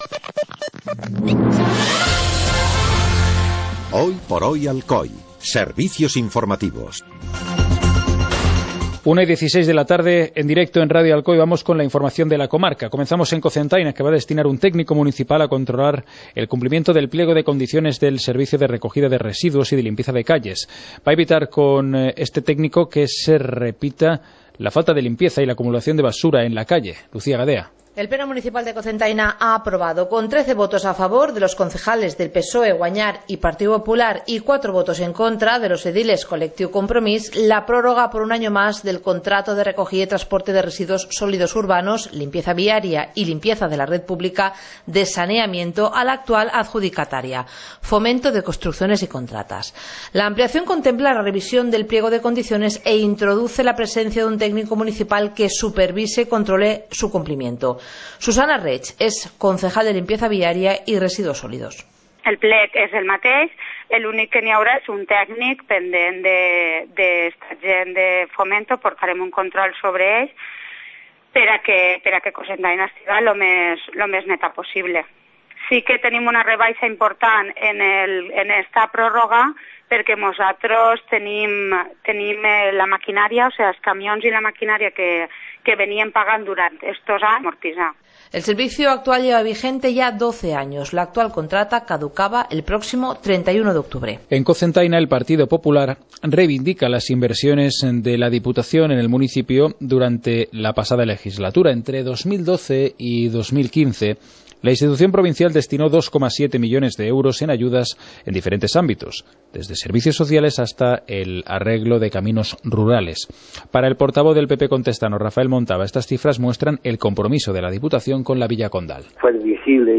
Informativo comarcal - martes, 03 de mayo de 2016